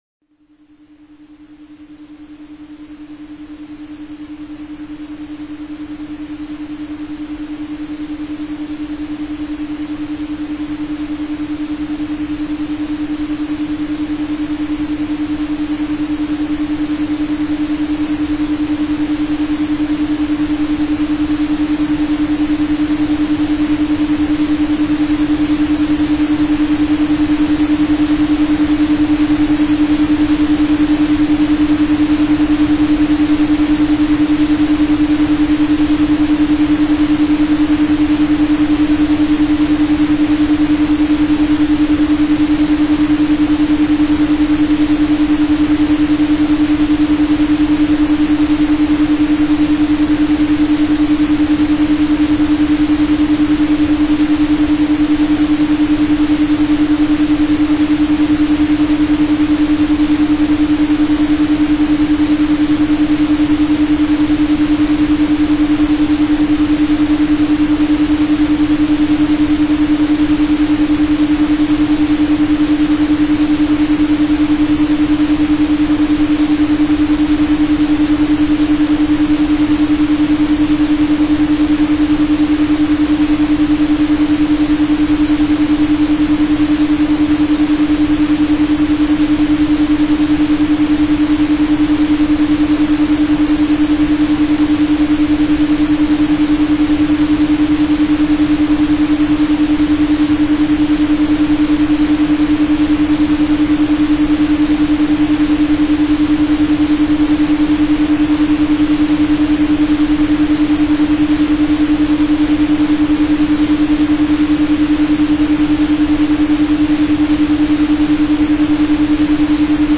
Цифровой наркотик (аудио наркотик) Экстази